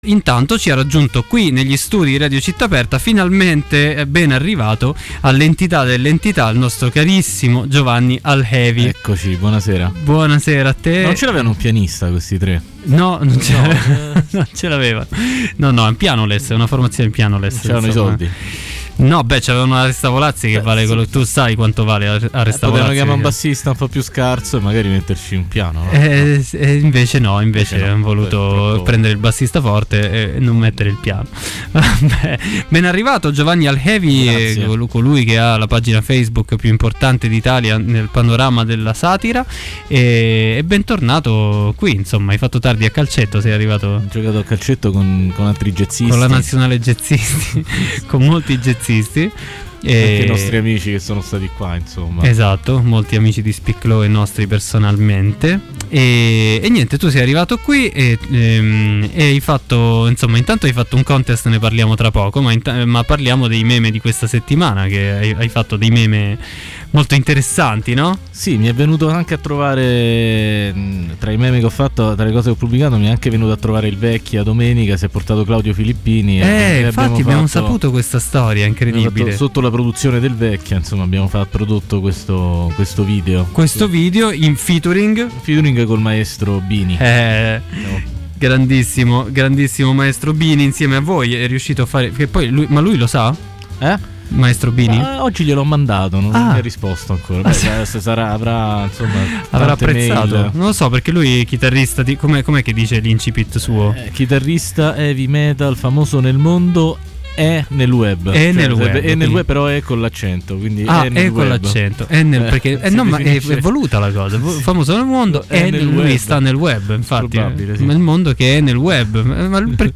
In diretta a Speak Low del 06.10.20